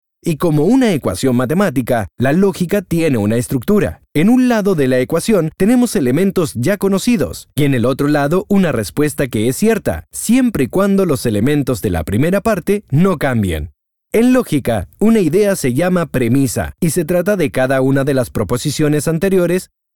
Dubbing Actor - Voiceover - Narrator - Voiceacting - and more
chilenisch
Sprechprobe: Industrie (Muttersprache):